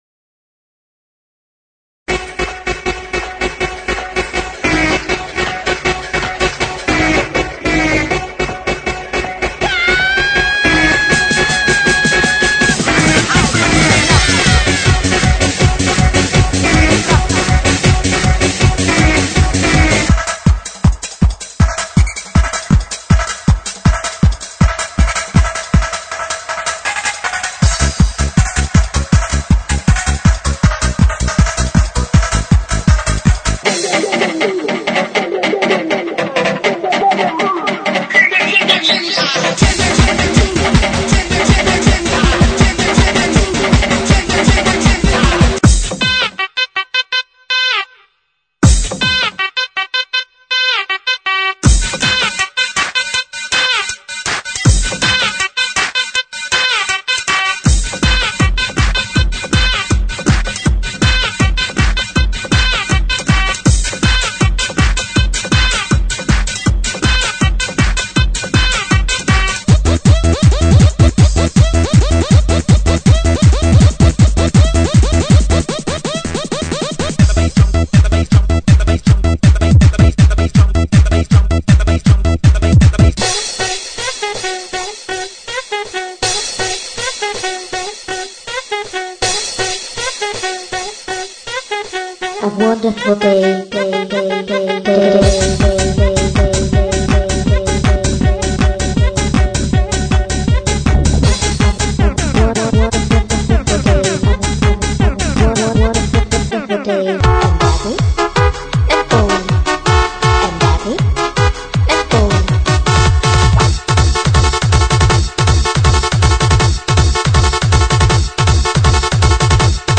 GENERO: ELECTRONICA